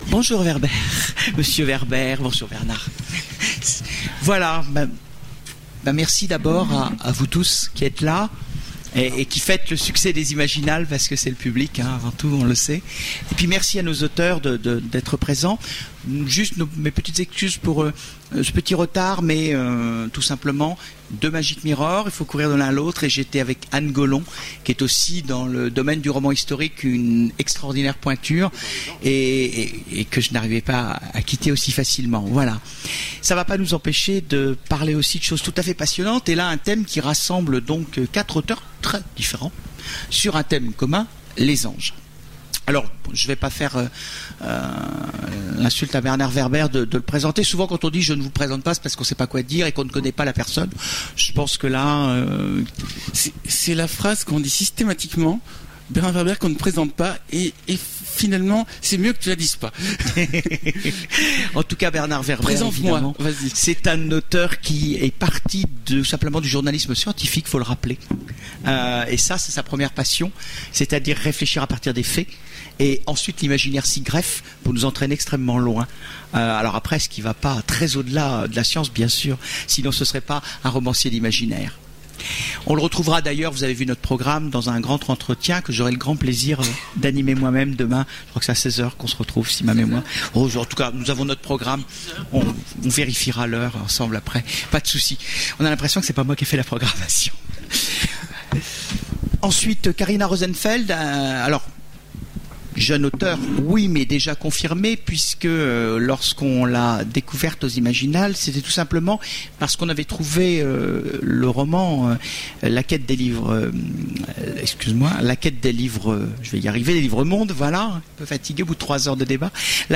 Voici l'enregistrement de la conférence Du côté des Anges… Un nouveau romantisme ? aux Imaginales 2010